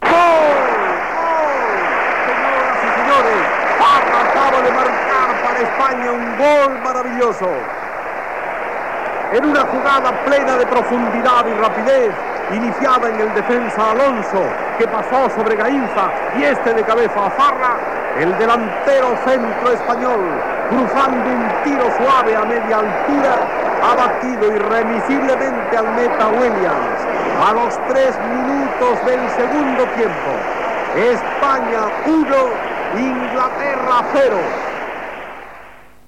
Tres recreacions diferents de la jugada fetes per Matías Prats, en no haver cap enregistrament original.
Esportiu